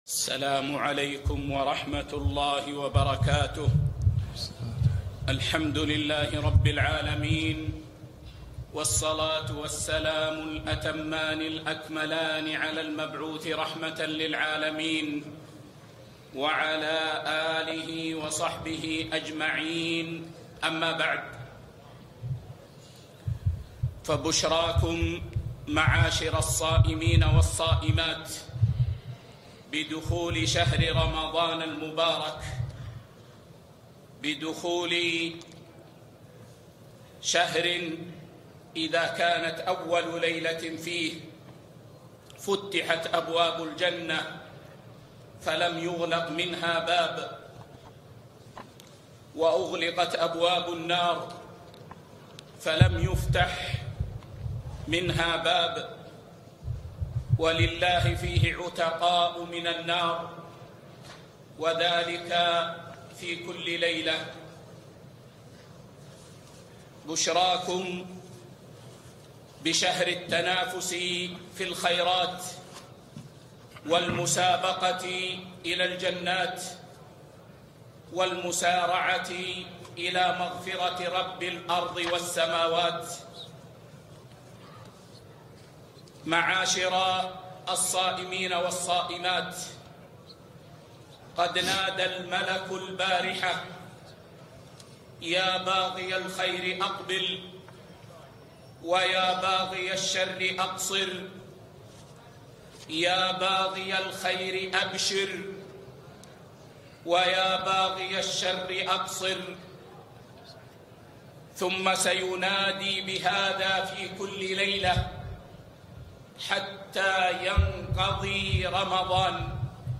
كلمة بمناسبة دخول شهر رمضان 1443